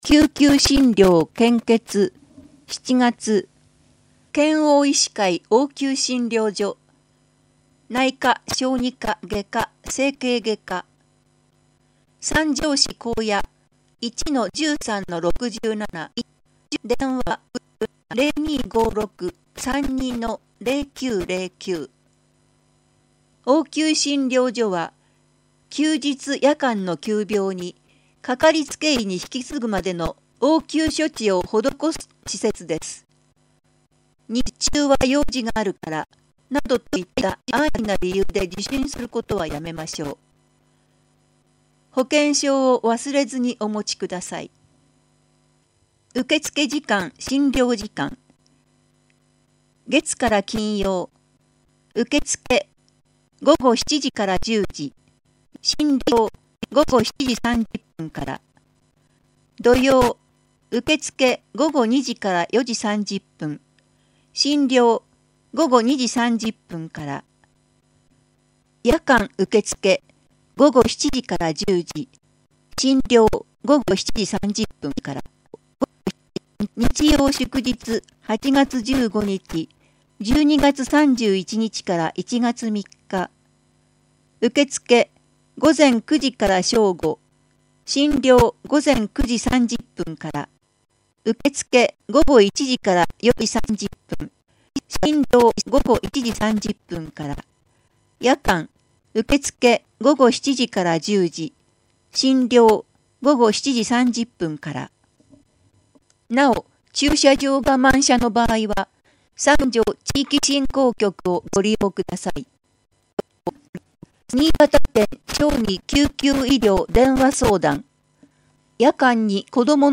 広報さんじょうを音声でお届けします